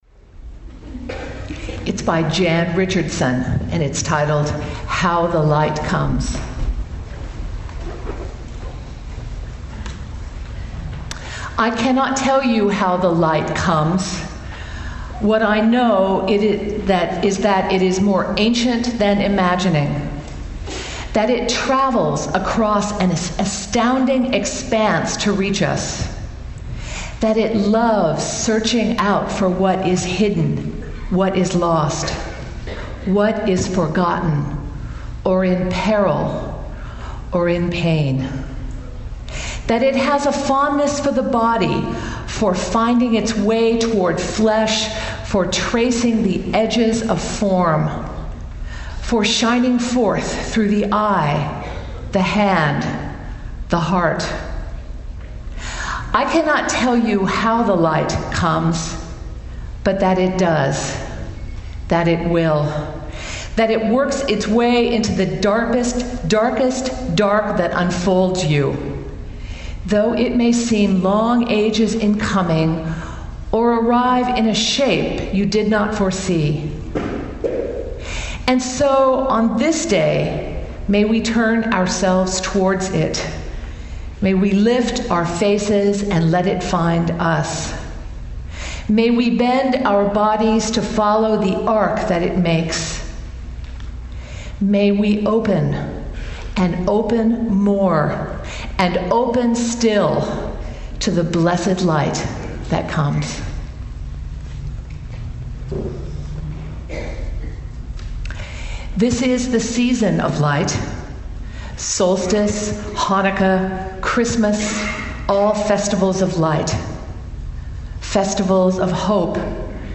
Audio clip is a brief (3 minute) excerpt from the service.